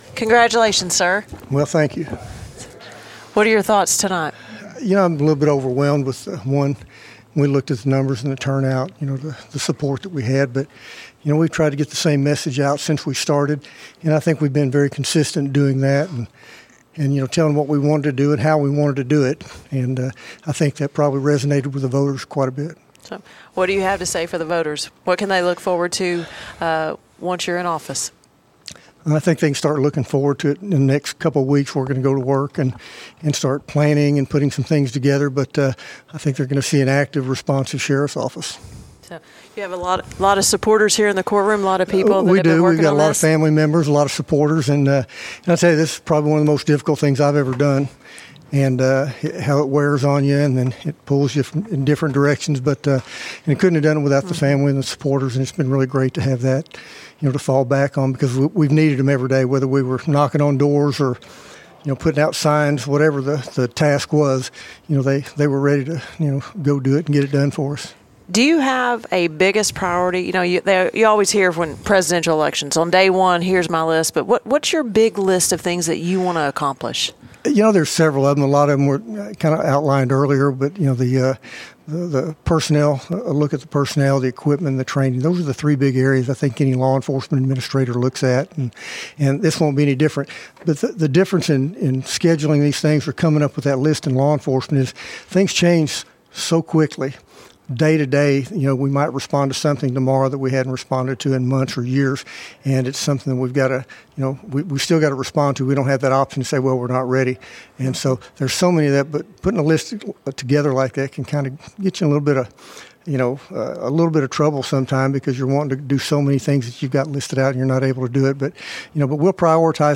KTLO, Classic Hits and The Boot News spoke with Sheriff elect Hollingsworth live at the Baxter County Courthouse Tuesday evening following the news of his victory.